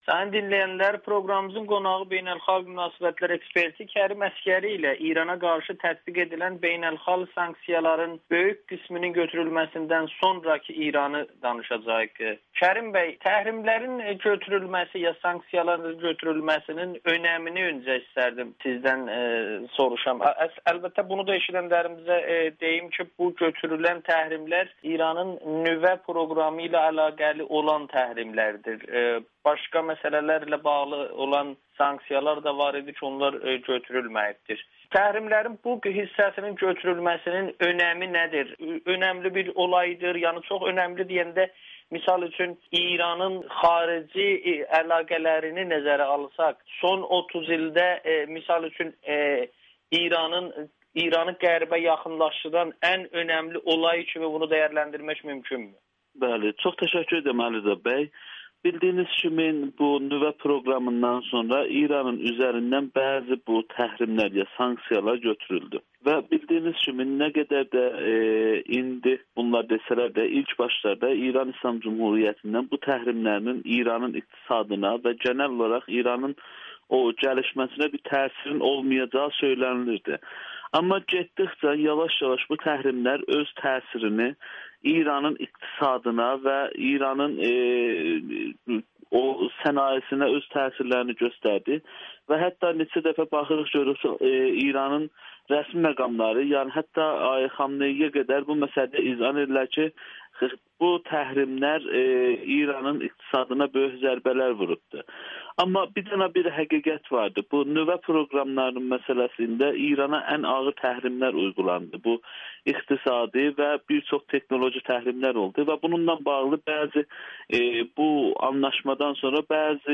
Sanksiyaların ləğvi ilə İran-Qərb münasibətləri normallaşacaqmı? [Audio-Müsahibə]